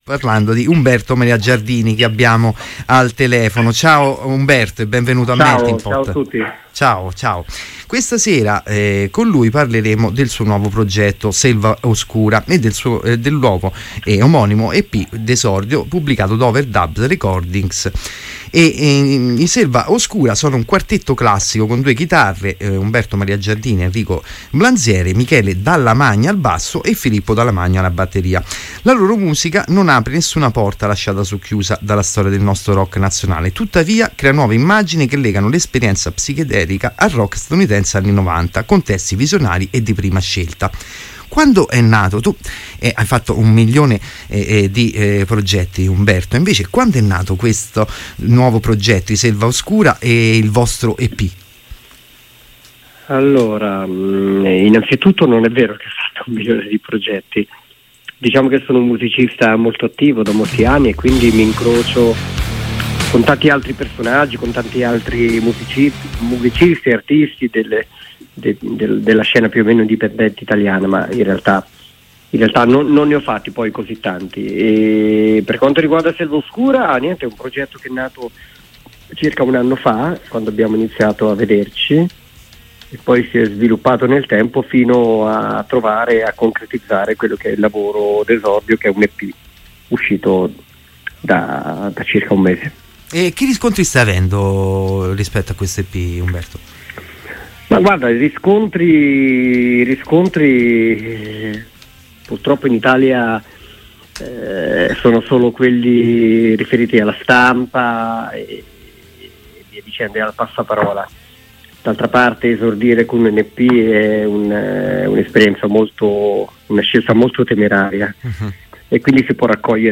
meltingpot-Intervista-SelvaOscura.mp3